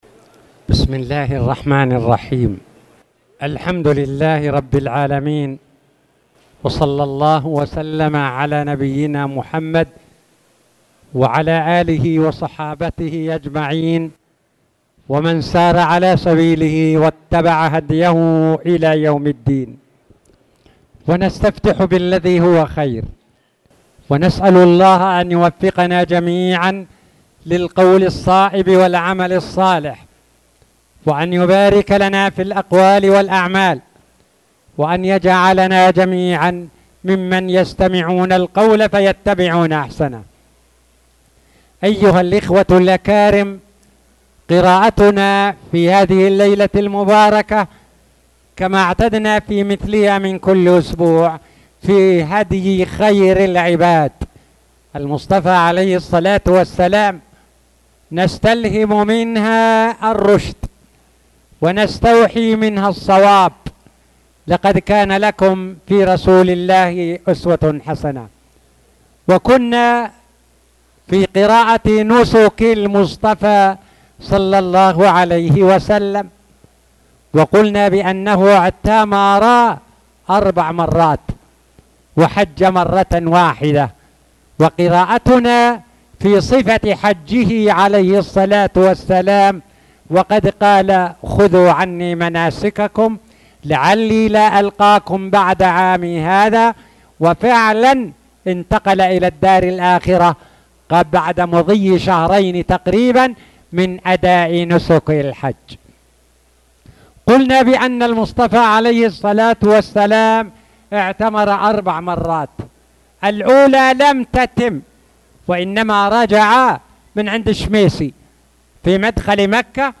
تاريخ النشر ١ ذو القعدة ١٤٣٧ هـ المكان: المسجد الحرام الشيخ